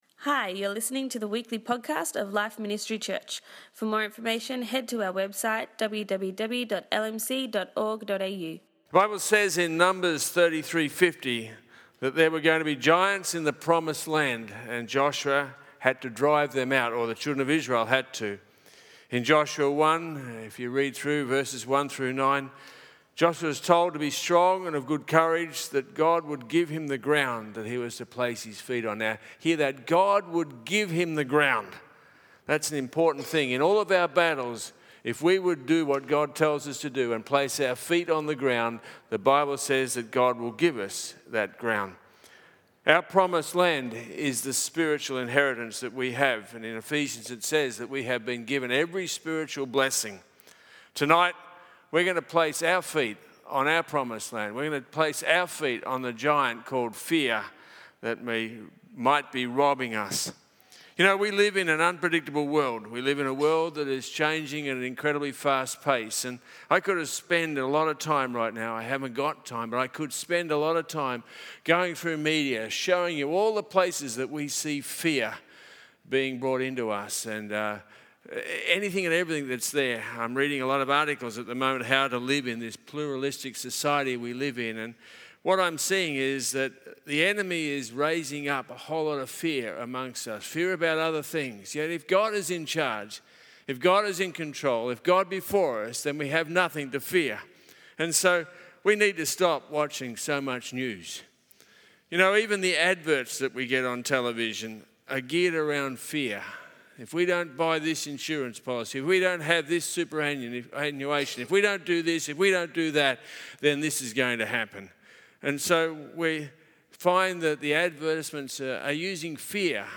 In this practical message, he gives us biblical principles on how to overcome fear in our lives.